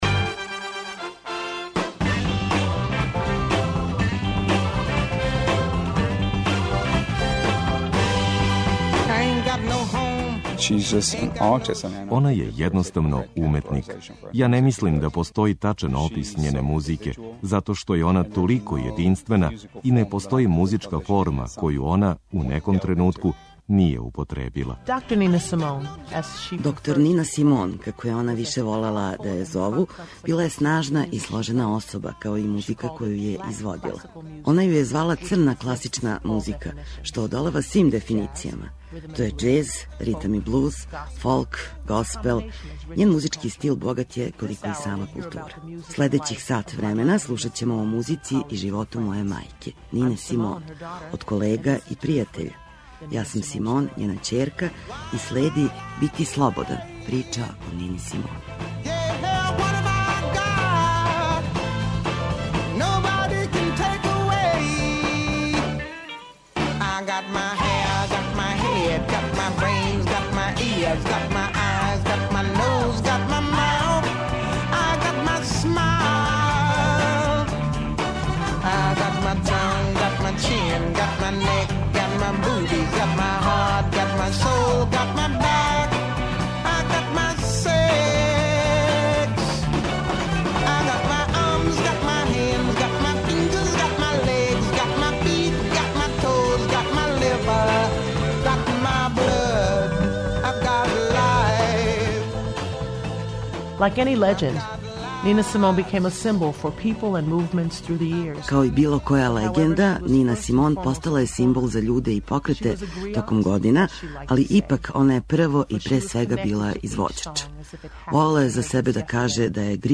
Слушаћете документарни радио програм о Нини Симон.